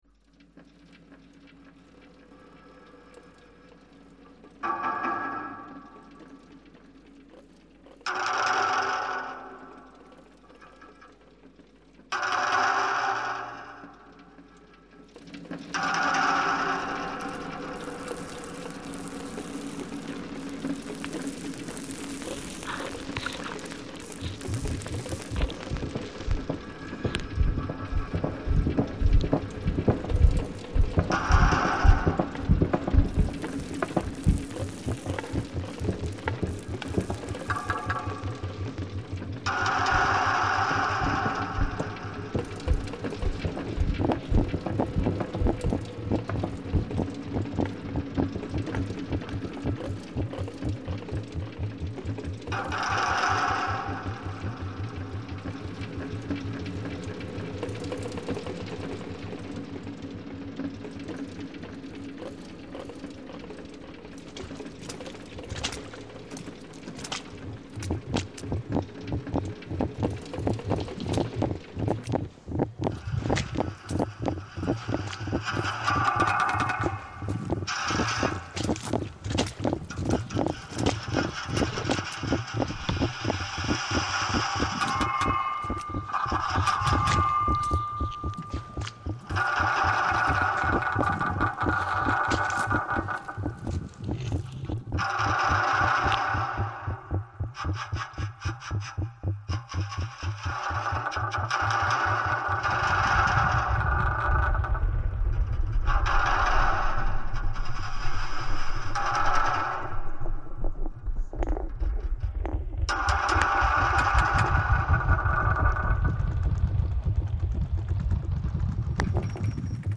‘Byway Route Hollow Lane’  (GB 2018) ::: is a composite soundscape made with manipulated field recordings from a Welsh walk and the results of aural experiments with a contact microphone… Tramping Downhill… Rocks on a Drum Skin… Barn Cladding Vibration… Deluge Dripping.
gb-byway-route-hollow-lane-gb-2018.mp3